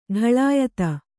♪ ḍhaḷāyata